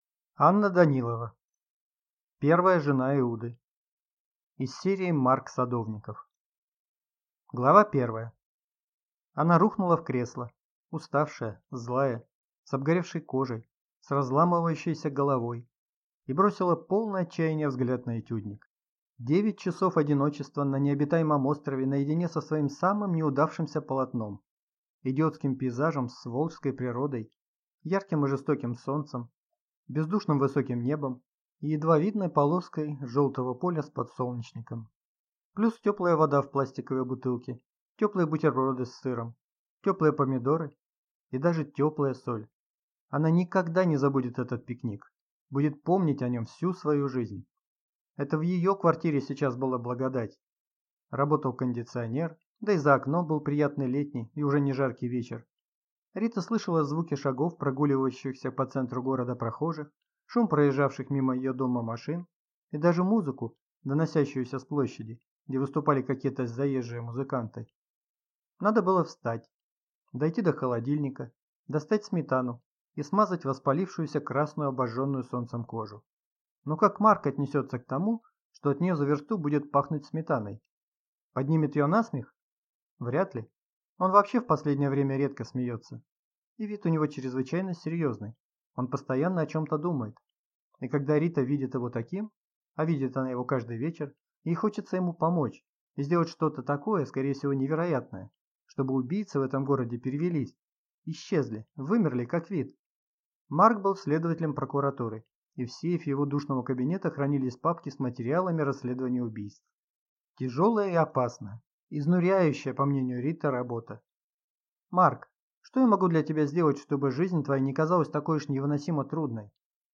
Аудиокнига Первая жена Иуды | Библиотека аудиокниг
Прослушать и бесплатно скачать фрагмент аудиокниги